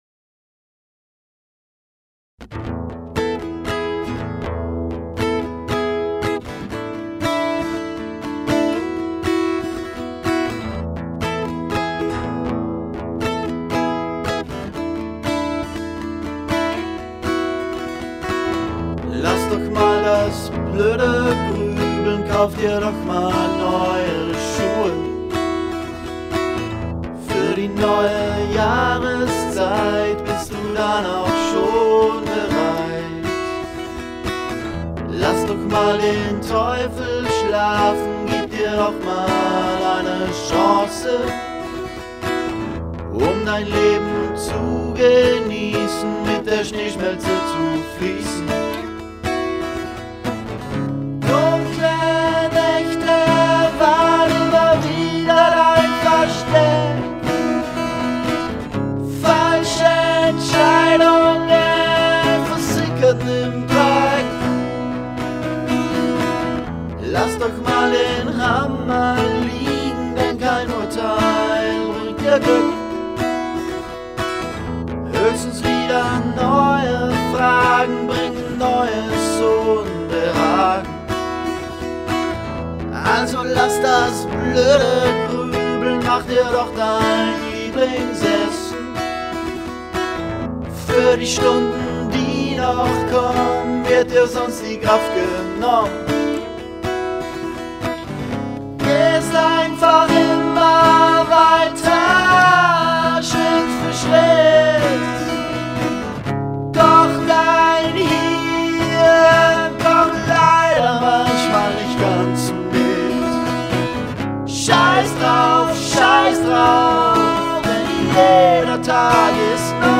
Rau und brutal und toll-wütig.